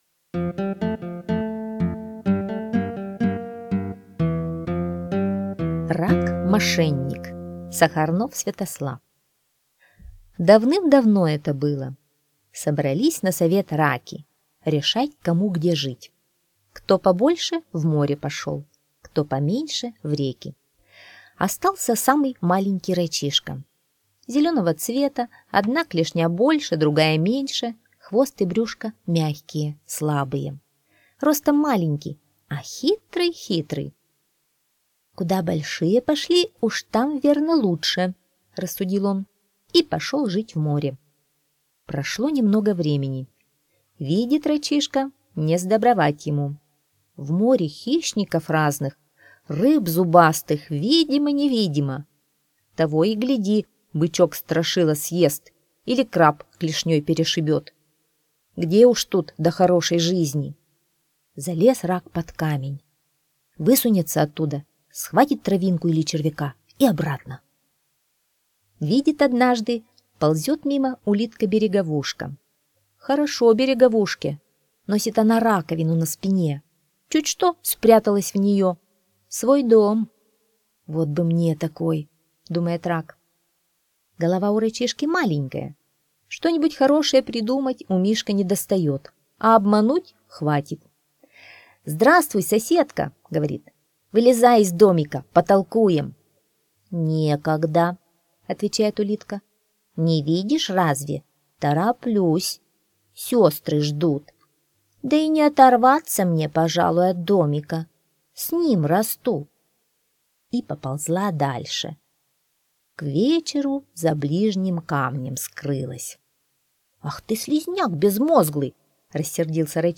Аудиосказка «Рак-мошенник»